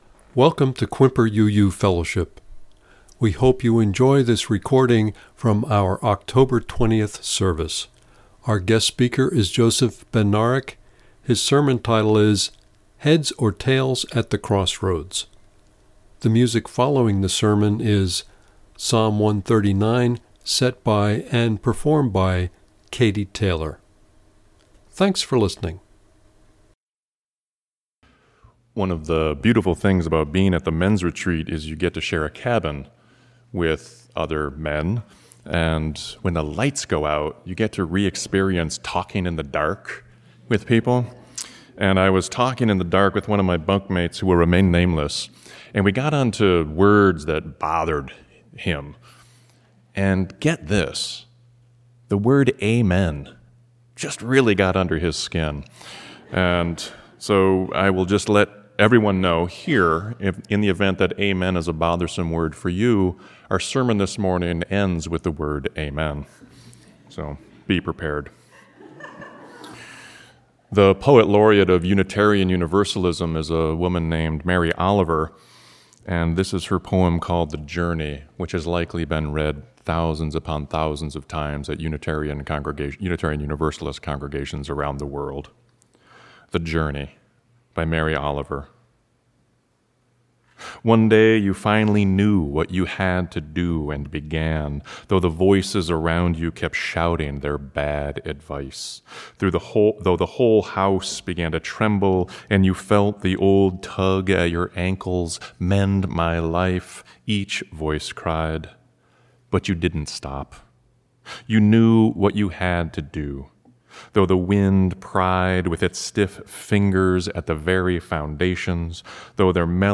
Click here to listen to the reading and sermon.